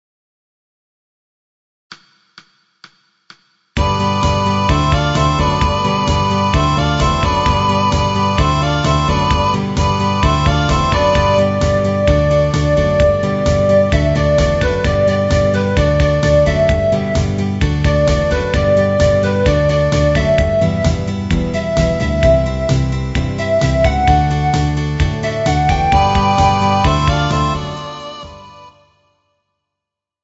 • Backing Track: Midi File